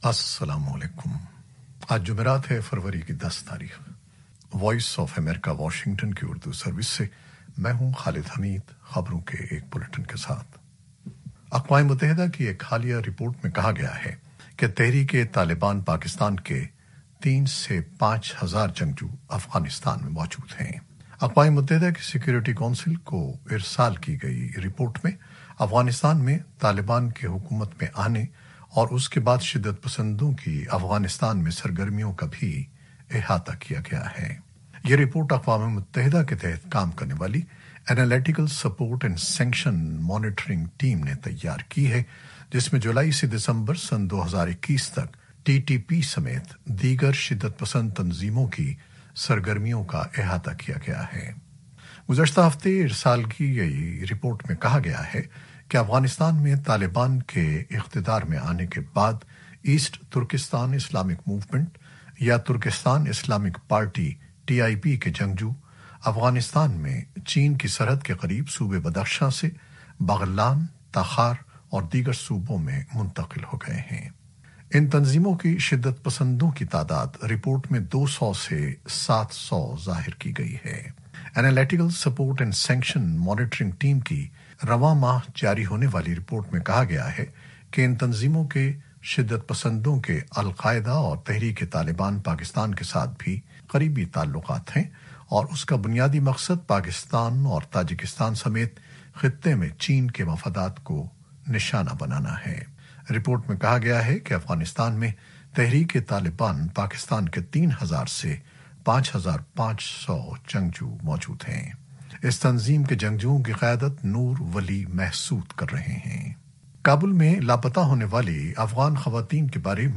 نیوز بلیٹن 2021-10-02